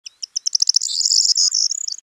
bird2.mp3